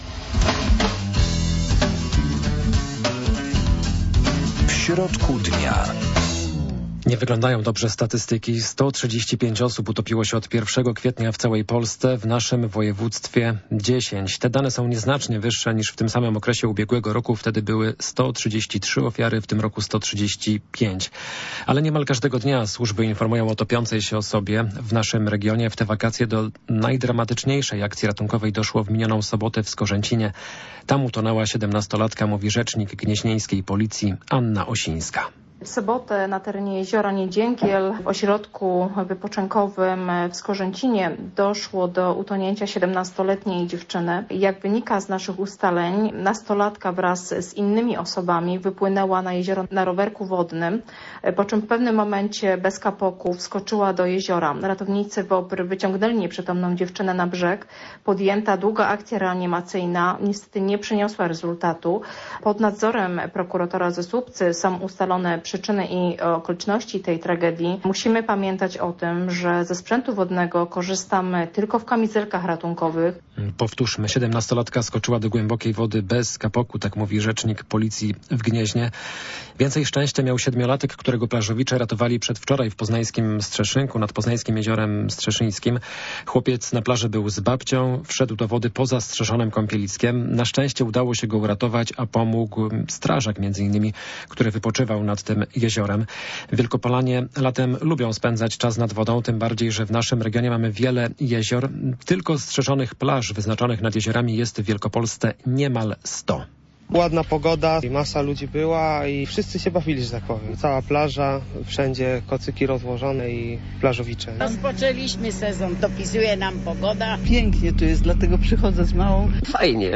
Dlaczego dochodzi do tylu tragedii nad wodą i o jakich zasadach nie można zapominać w trakcie wypoczynku nad jeziorem? O tym porozmawiamy ze słuchaczami w audycji "W środku dnia" o godzinie 12.15.